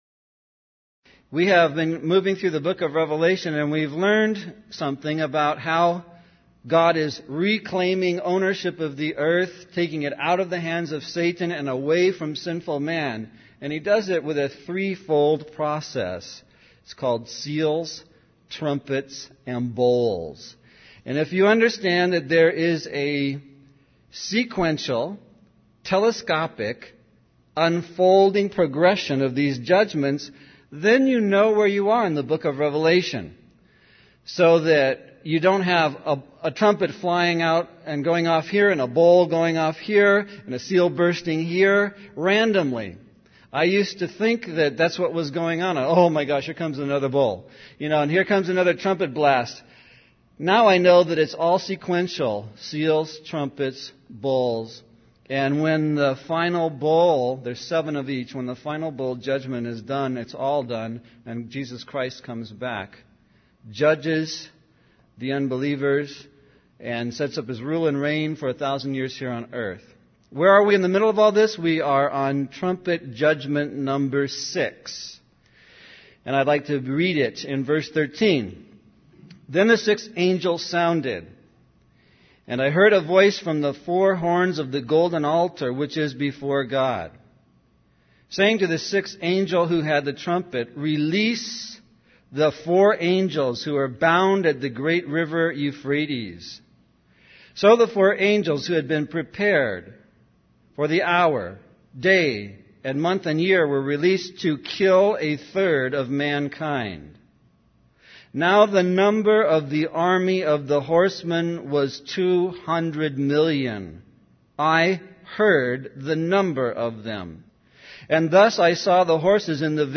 In this sermon, the speaker discusses the concept of cosmic warfare between fallen angels.